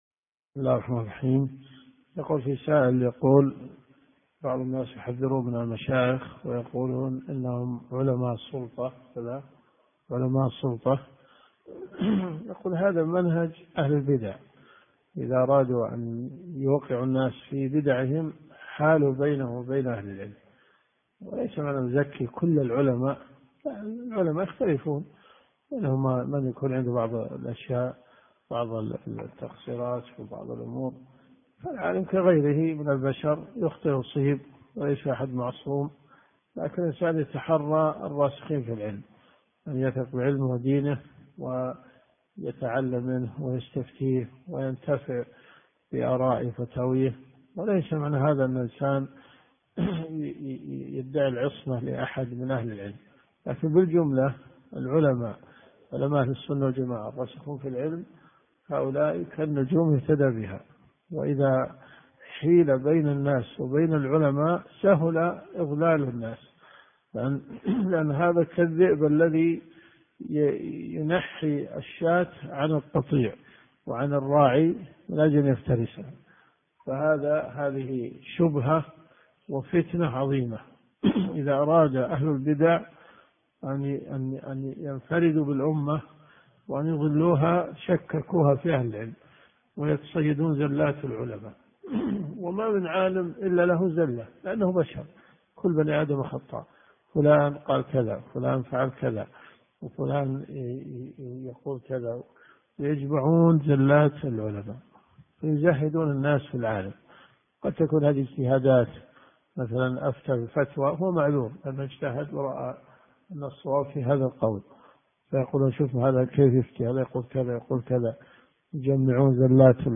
صحيح مسلم . كتاب الجمعة . من حديث 1951 -إلى- حديث 1964 . الدرس في الدقيقة 2.25 .